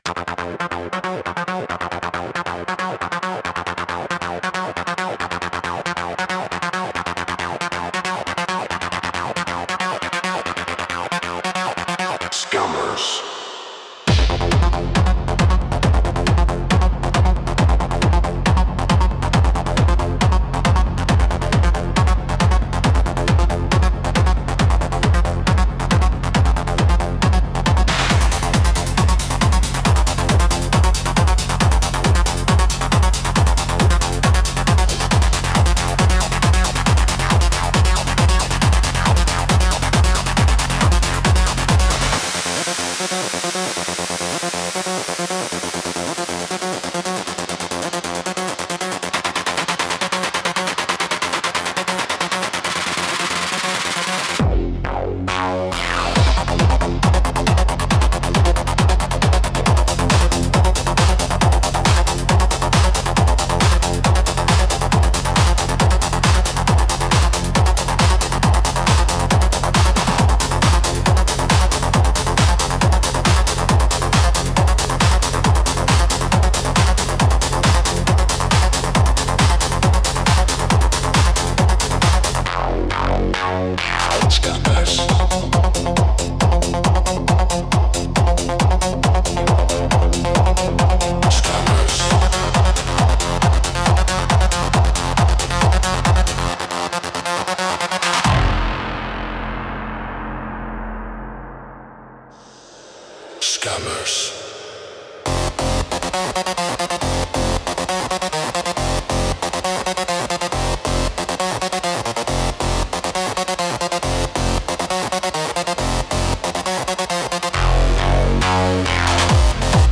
EDM Music “Scammers”